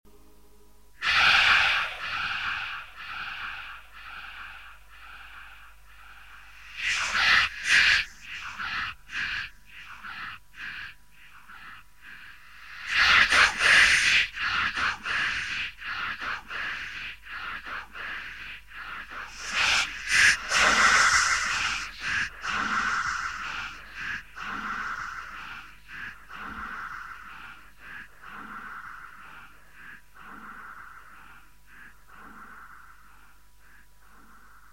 ヴォコーダによるメッセージ（ホワイト・ノイズをヴォコーダで変調）